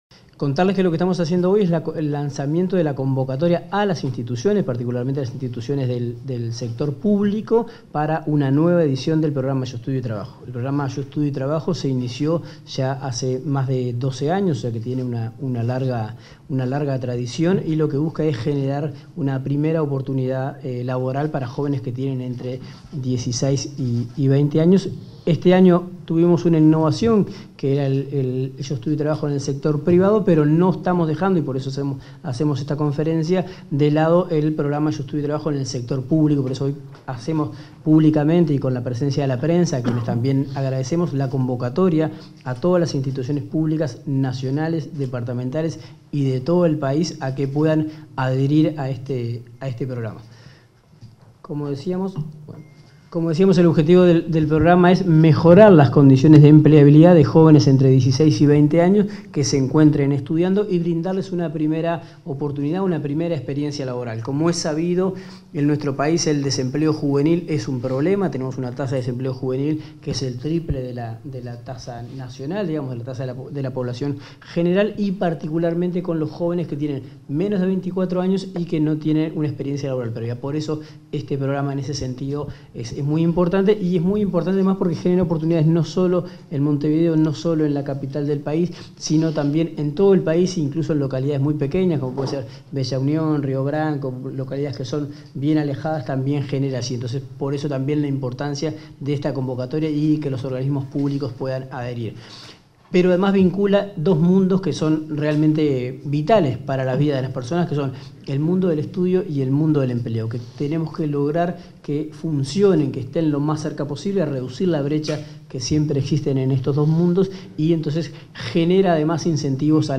Palabras del subsecretario del MTSS, Daniel Pérez
En el marco de la ceremonia de lanzamiento de la 13.ª edición del programa Yo Estudio y Trabajo, este 20 de junio, se expresó el subsecretario del